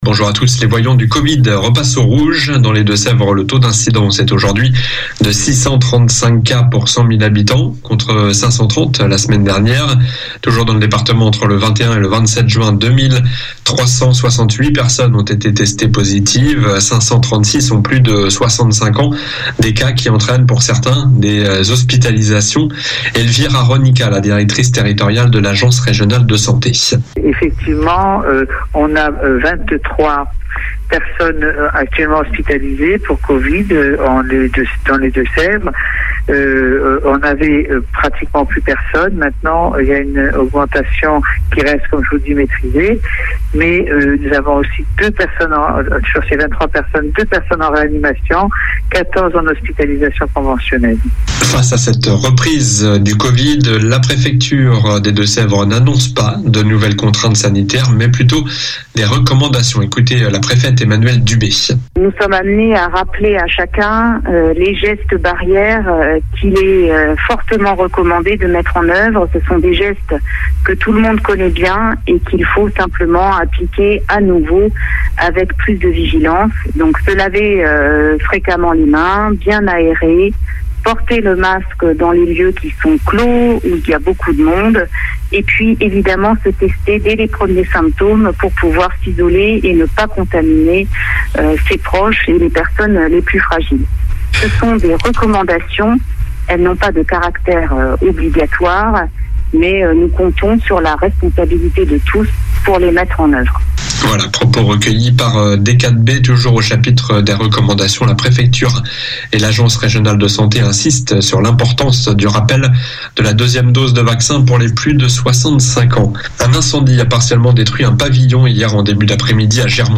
Journal du samedi 2 juillet